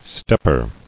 [step·per]